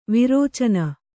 ♪ virēcana